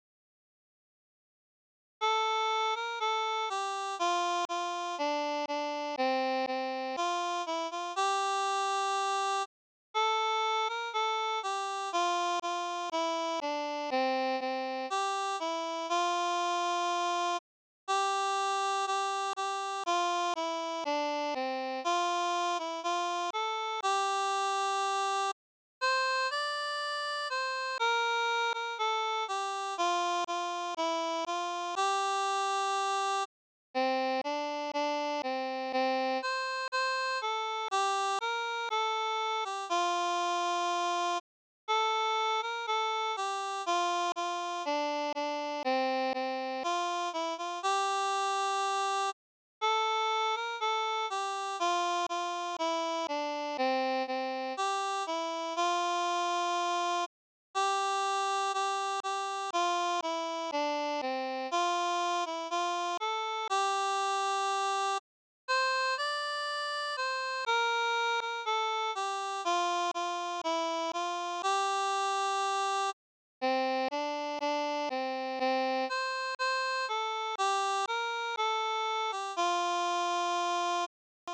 オリジナル作品による学級対抗の合唱コンクール